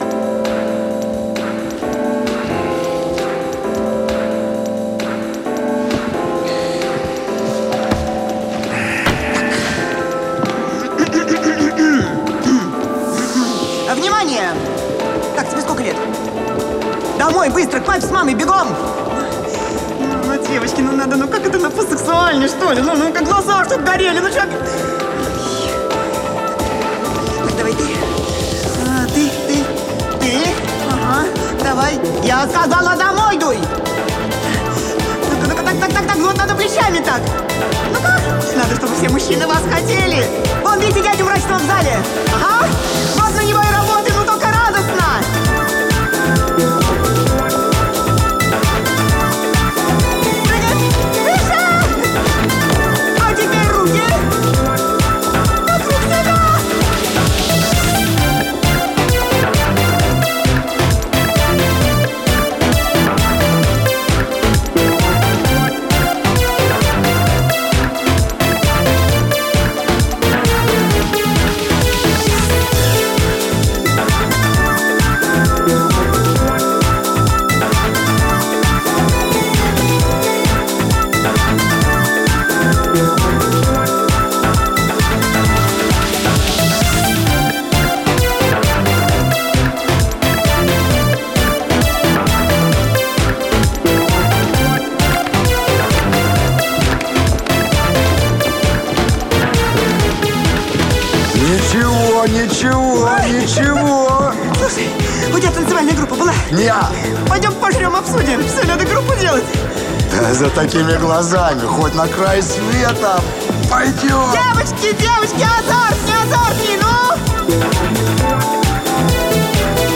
Это дорожка из сериала с речью: